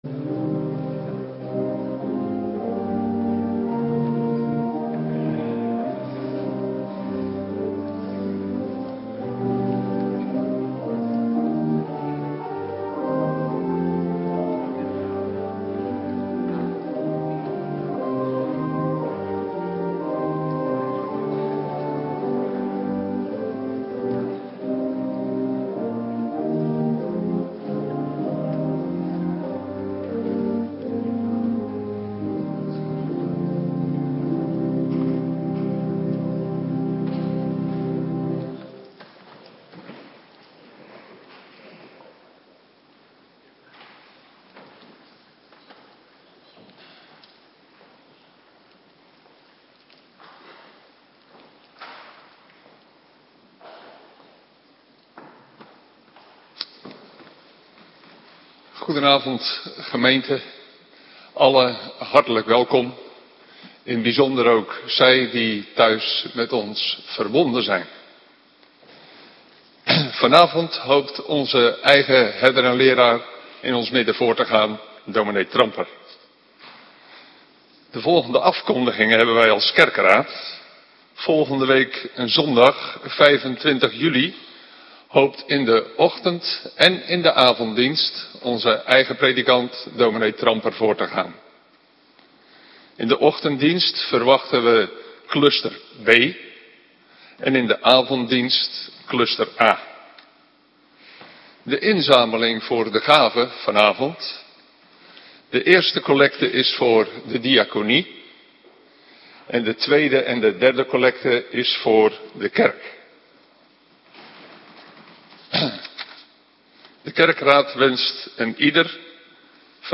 Avonddienst - Cluster B
Locatie: Hervormde Gemeente Waarder